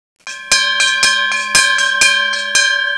jackpot.wav